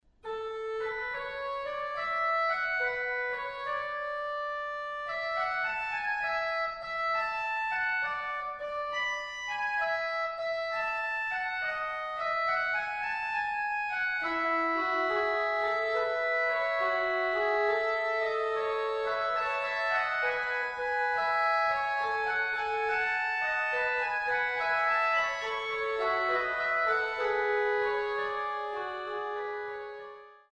Hur låter orglarna? Från varje orgel  finns ljudexempel.
Allhelgonakyrkan i Malmberget                     Orgeln i Allhelgonakyrkan i Malmbergerget